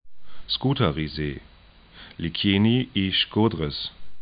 Pronunciation
'skuta:rize: